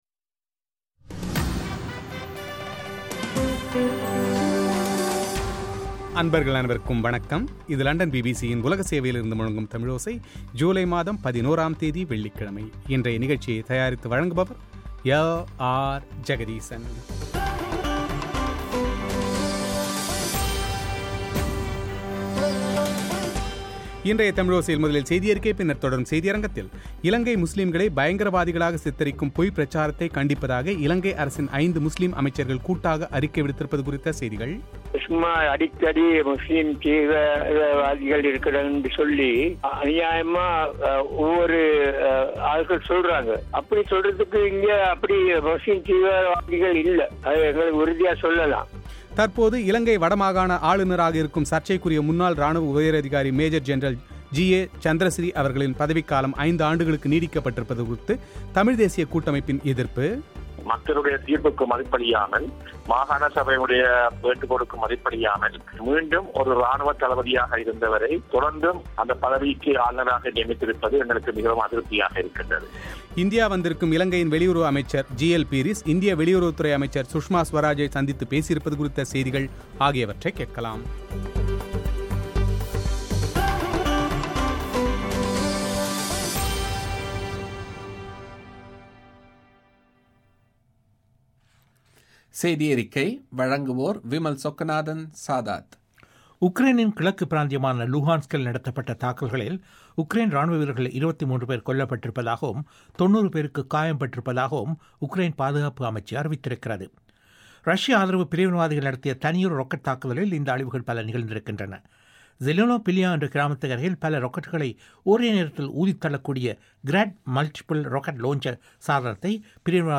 இலங்கை முஸ்லீம்களை பயங்கரவாதிகளாக சித்தரிக்கும் பொய்பிரச்சாரத்தை கண்டிப்பதாக இலங்கை அரசின் ஐந்து முஸ்லீம் அமைச்சர்கள் கூட்டறிக்கை விடுத்திருப்பது குறித்து இலங்கையின் மூத்த முஸ்லீம் அமைச்சர் ஏ.எச்.எம்.பௌஸியின் செவ்வி;